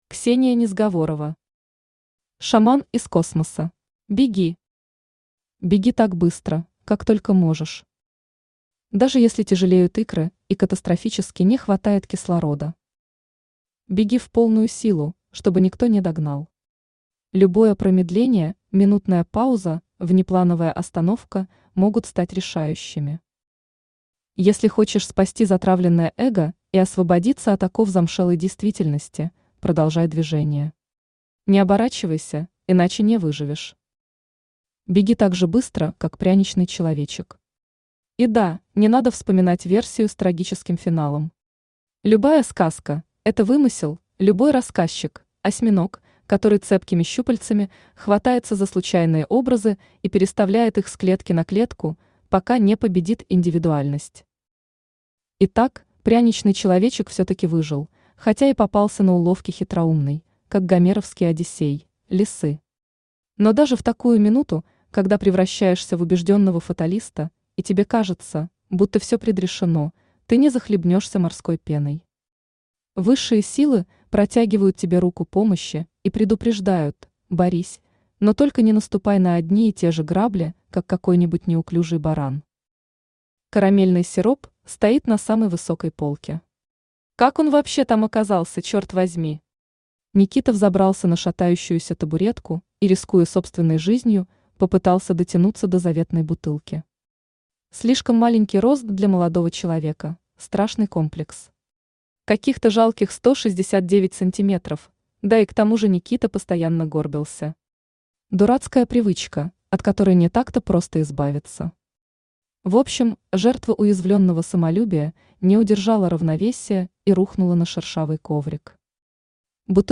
Аудиокнига Шаман из космоса | Библиотека аудиокниг
Aудиокнига Шаман из космоса Автор Ксения Викторовна Незговорова Читает аудиокнигу Авточтец ЛитРес.